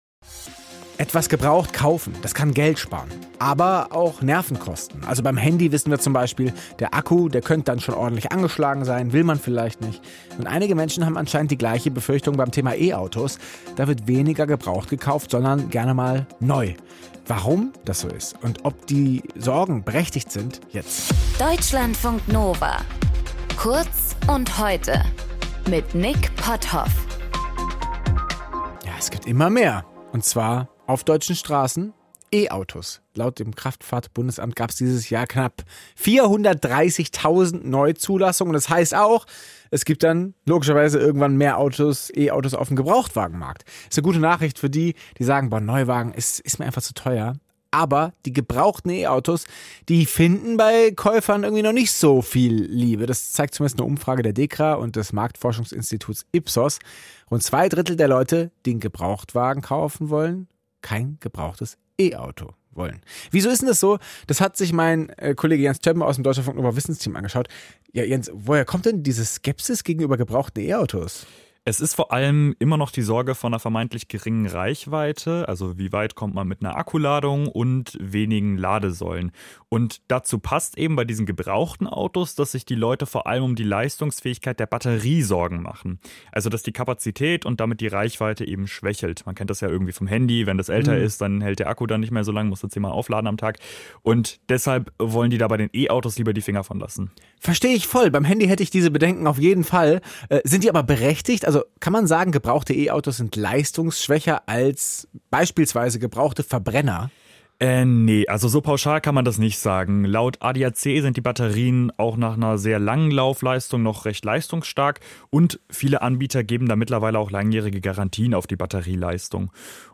Moderator:
Gesprächspartner: